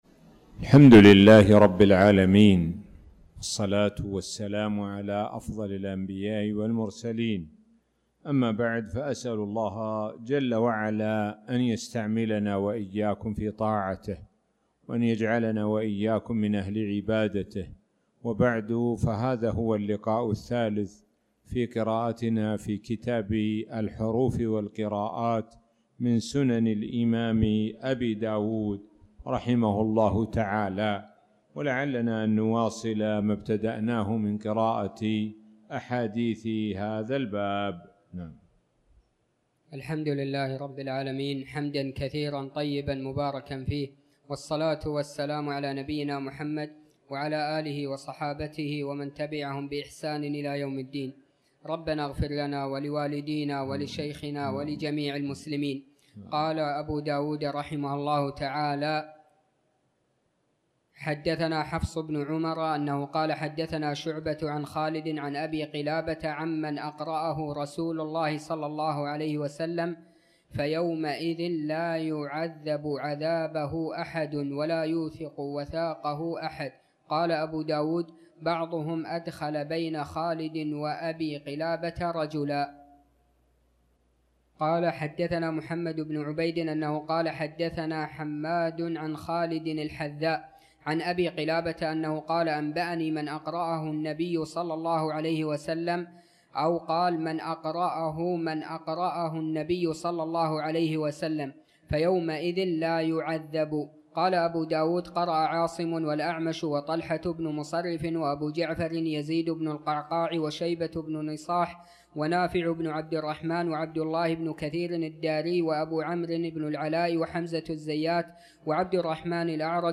تاريخ النشر ٢٢ شوال ١٤٤٠ هـ المكان: المسجد الحرام الشيخ: معالي الشيخ د. سعد بن ناصر الشثري معالي الشيخ د. سعد بن ناصر الشثري كتاب اللباس